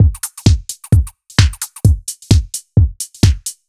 Index of /musicradar/uk-garage-samples/130bpm Lines n Loops/Beats